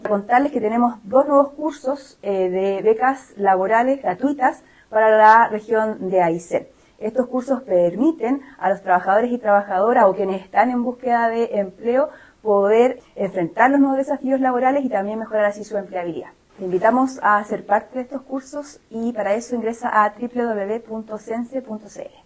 Seremi del Trabajo y Previsión Social